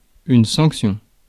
Ääntäminen
Synonyymit consécration Ääntäminen France Tuntematon aksentti: IPA: /sɑ̃k.sjɔ̃/ Haettu sana löytyi näillä lähdekielillä: ranska Käännöksiä ei löytynyt valitulle kohdekielelle.